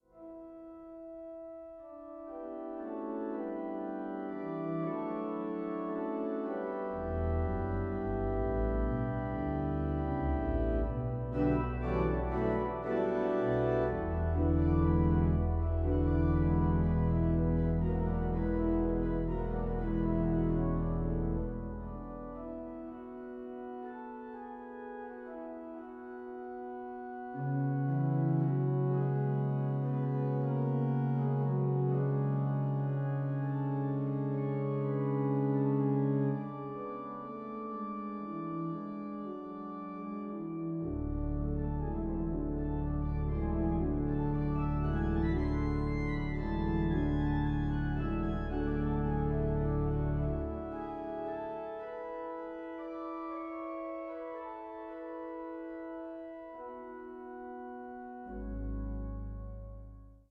main organ of Laurenskerk